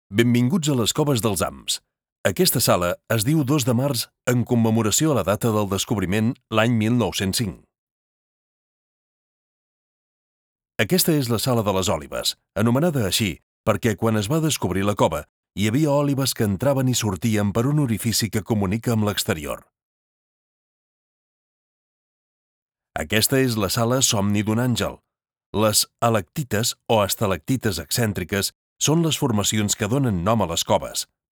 Commercieel, Vriendelijk, Warm, Zacht, Zakelijk
Audiogids